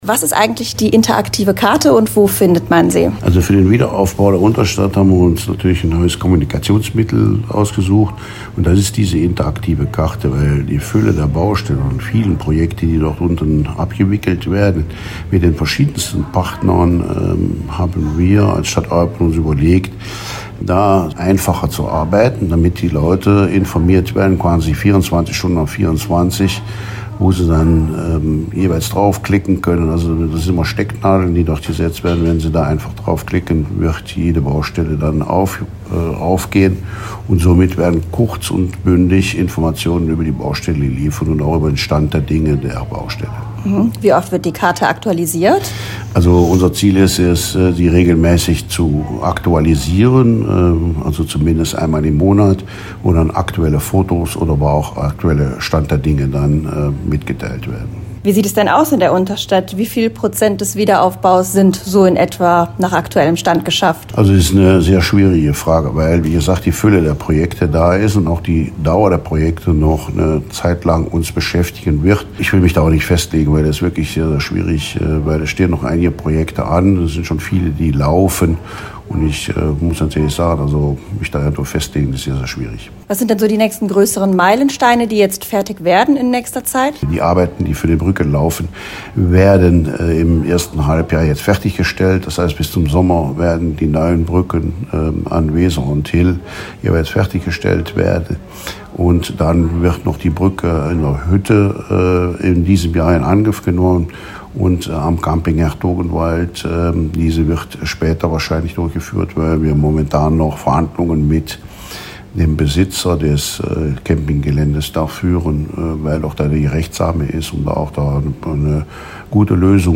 war für uns dabei und hat mit dem Eupener Bauschöffen, Michael Scholl, gesprochen: